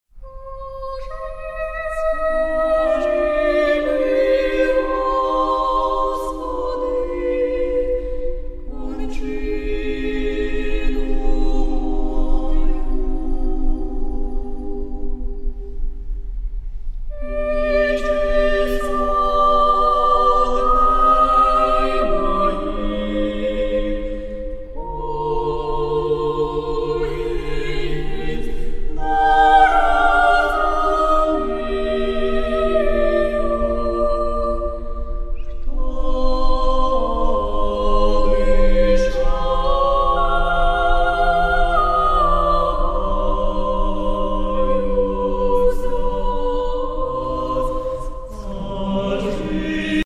Початок » CDs» Релігійна Мій аккаунт  |  Кошик  |  Замовити